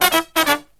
Index of /90_sSampleCDs/USB Soundscan vol.29 - Killer Brass Riffs [AKAI] 1CD/Partition D/03-133PERFS1